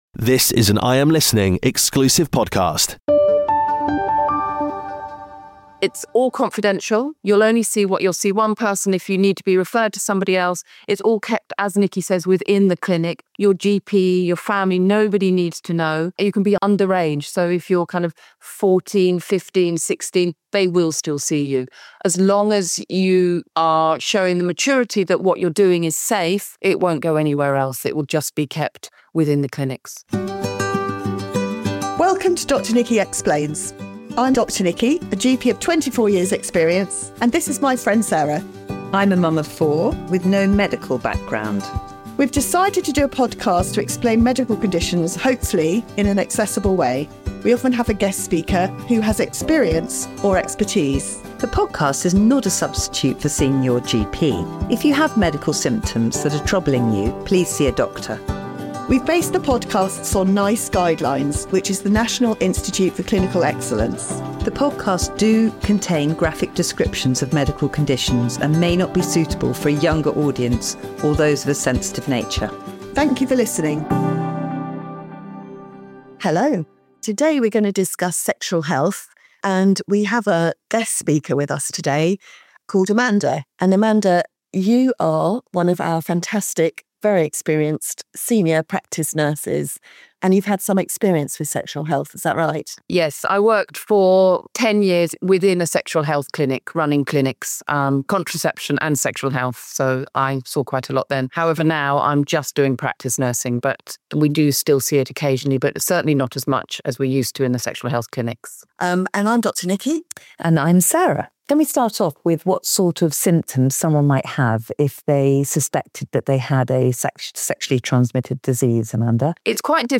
From symptoms to prevention, this conversation is packed with essential insights.